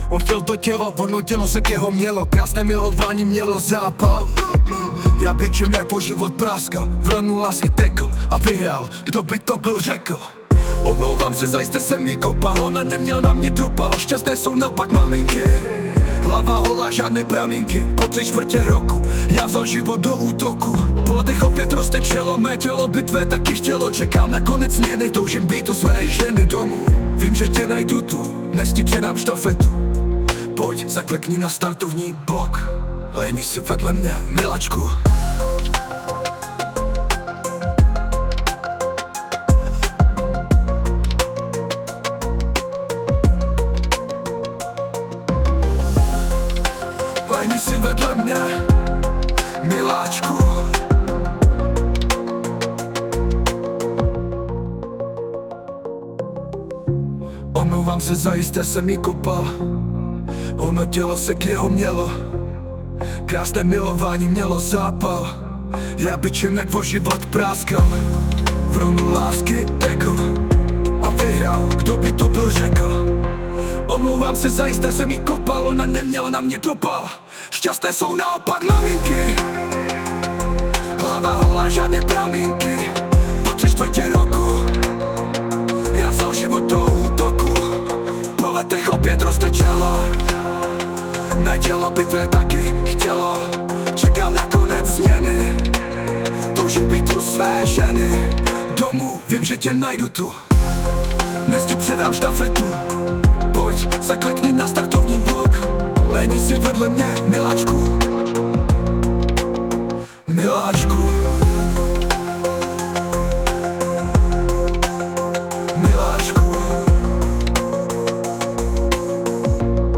* hudba,zpěv: AI